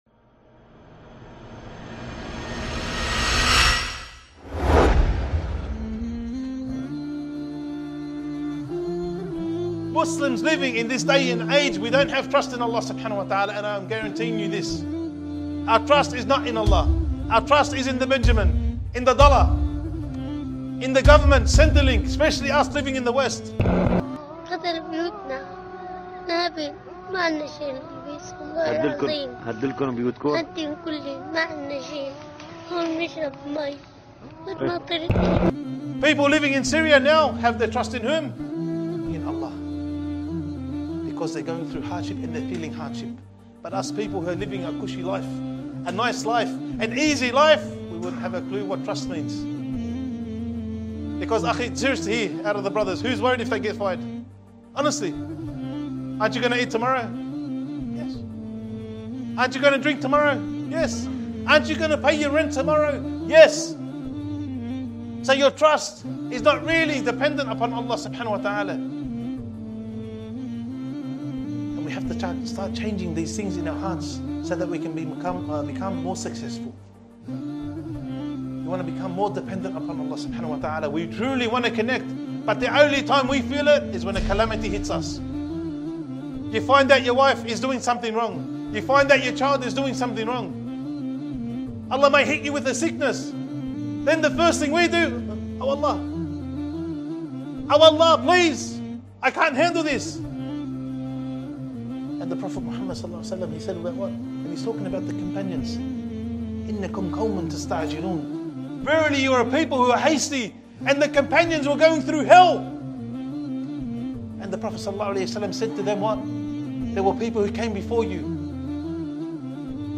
A short reminder by